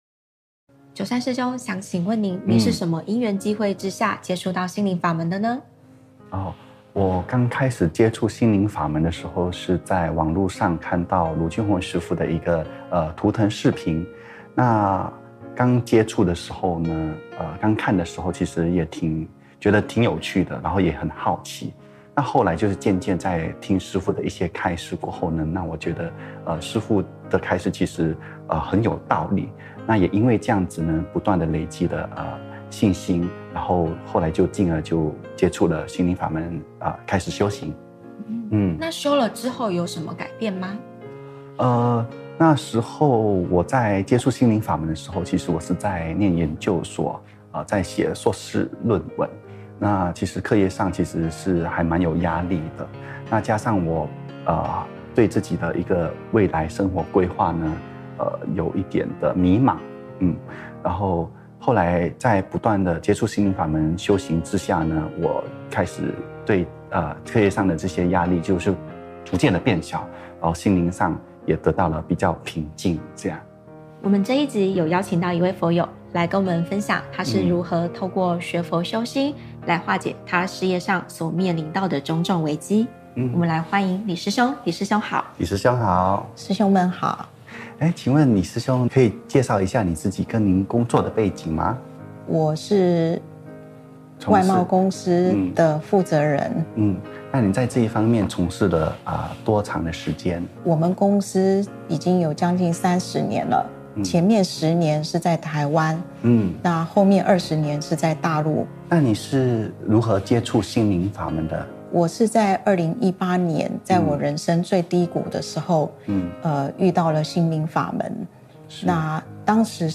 【訪談分享】08 ☞点击这里：下载 mp3 音频 【〔视频〕佛學會訪談！